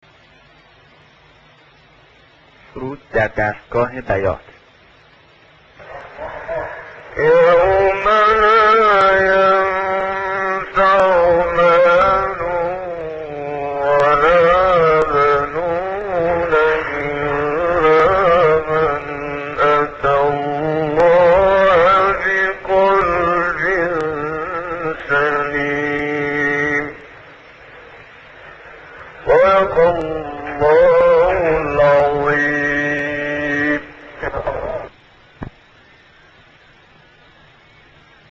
سایت-قرآن-کلام-نورانی-منشاوی-فرود-در-نغمه-بیات.mp3